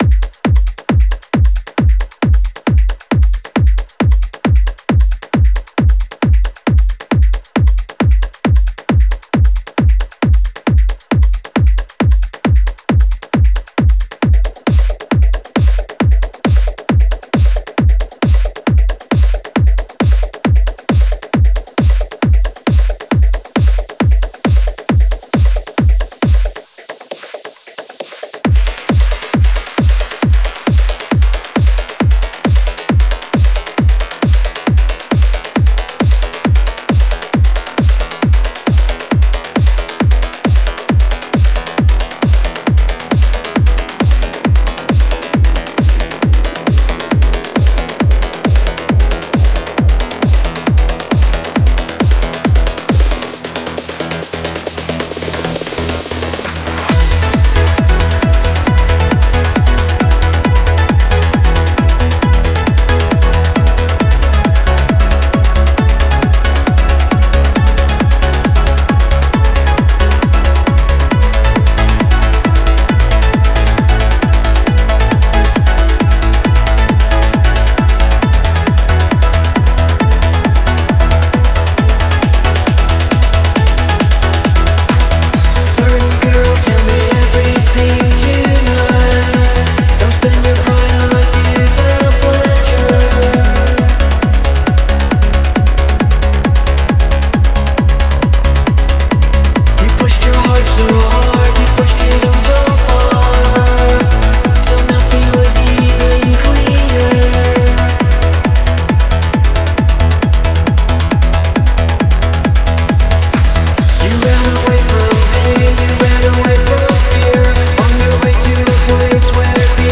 Genre: Synthwave.